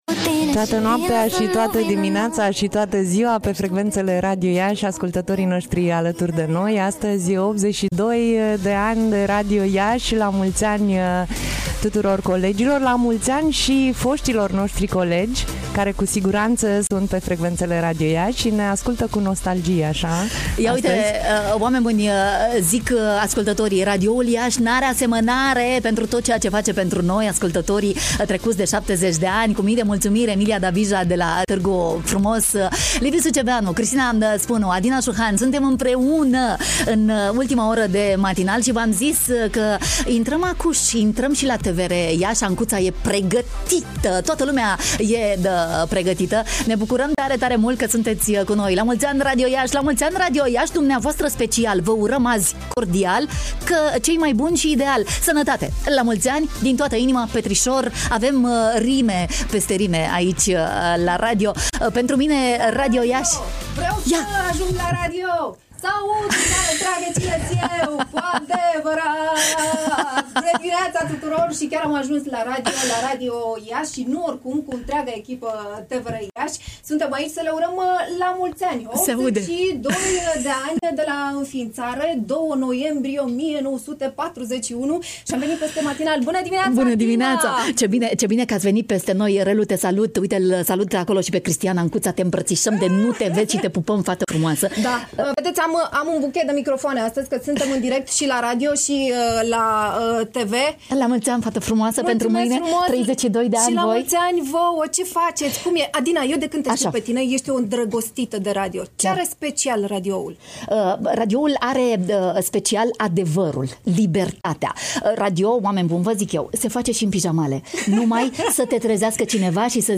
Echipa TVR Iași ne-a făcut o surpriză.